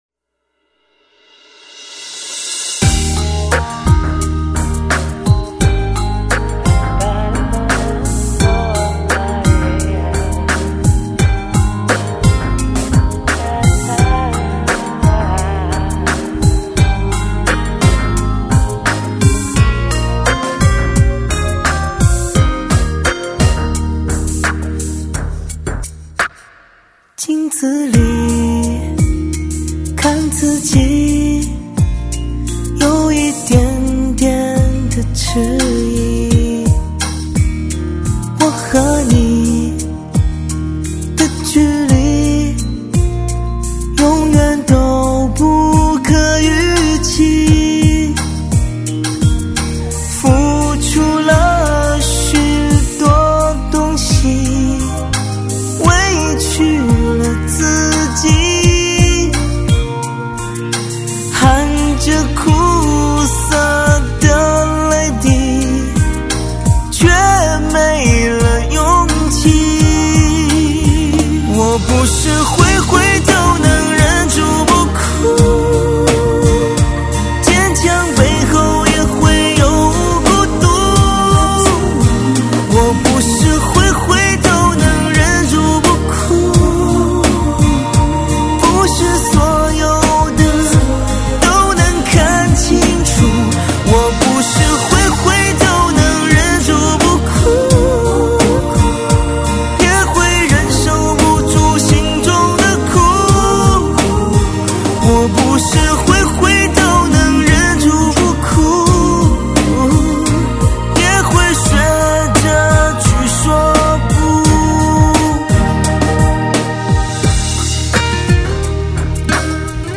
性别：男